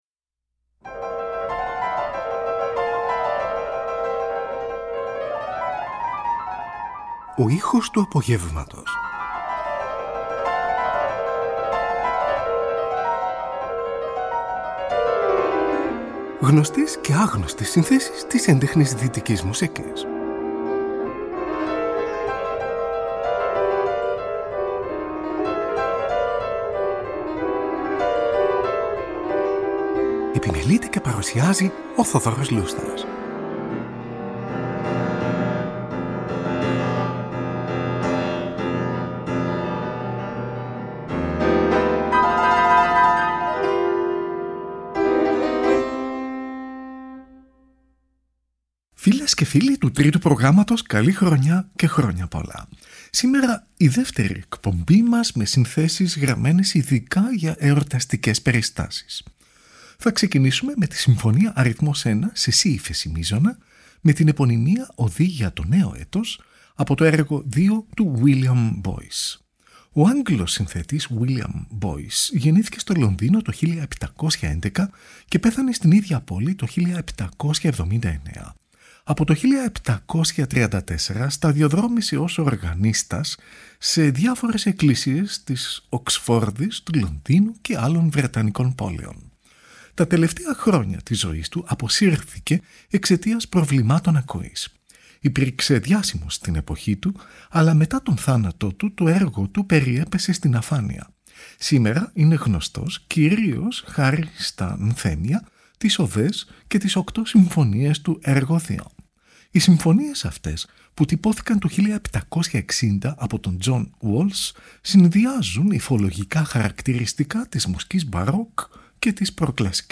γνωστές και άγνωστες συνθέσεις της Έντεχνης Δυτικής Μουσικής, από τον Μεσαίωνα μέχρι την εποχή μας
Johann Sebastian Bach : δύο μέρη από τη Θρησκευτική καντάτα “Jesu, nun sei gepreiset”, BWV 41.
Πρώτη παγκόσμια ηχογράφηση της καντάτας, από ζωντανή ραδιοφωνική μετάδοση , το 1950 .